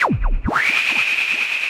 RI_RhythNoise_140-02.wav